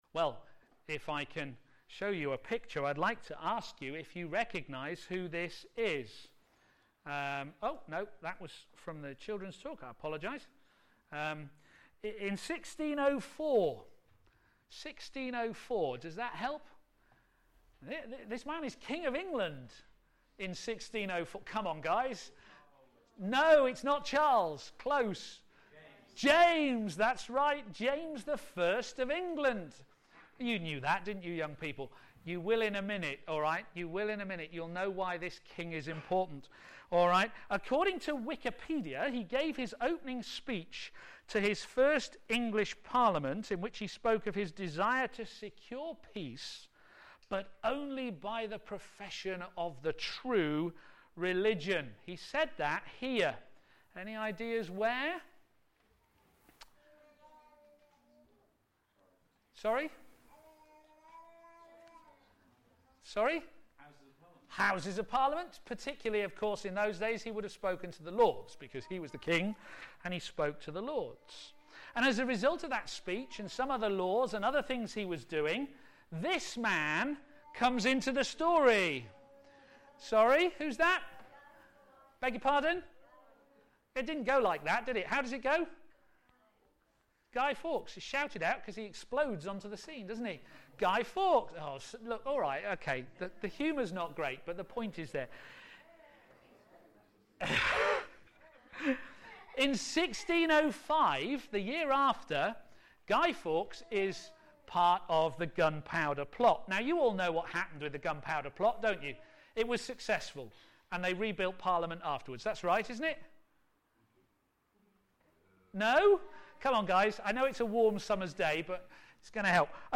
Media for a.m. Service
Discipleship in an Instant Society Theme: If God be for us Sermon